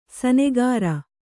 ♪ sanegāra